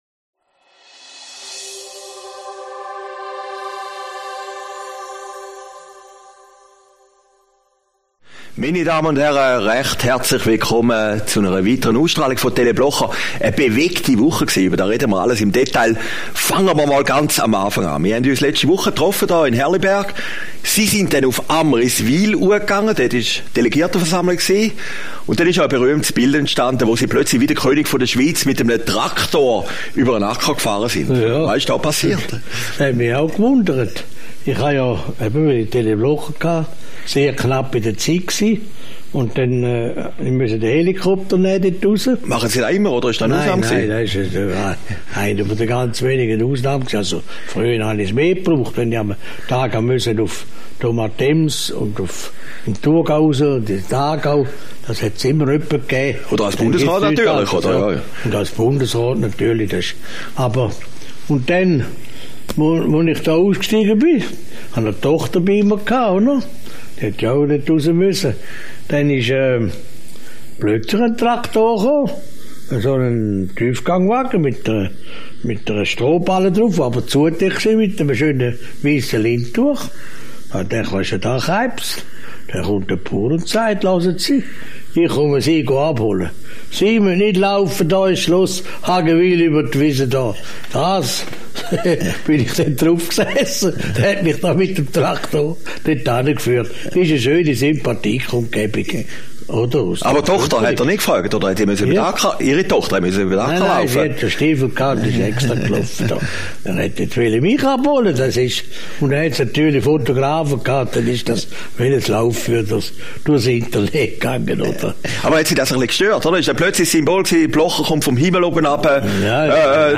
Aufgezeichnet in Herrliberg, 5. April 2019